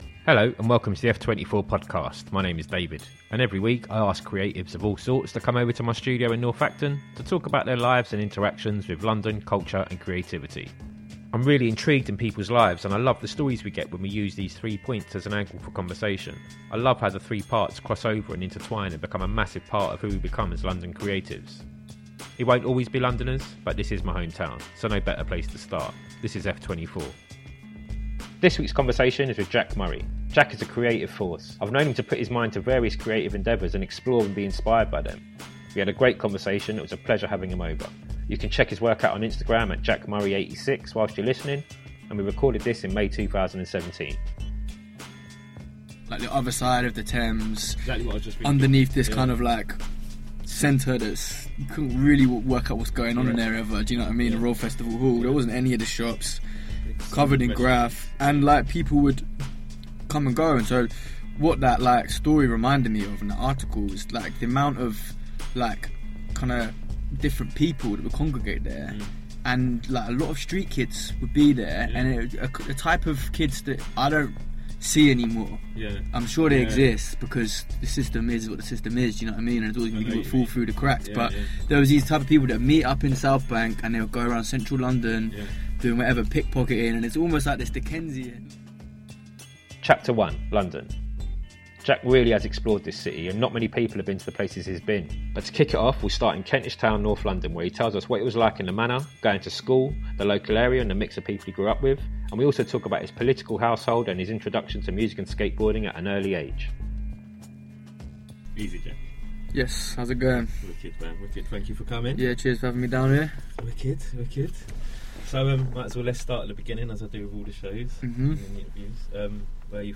artist and activist came over to the F24 Studios to talk London, Culture and Creativity.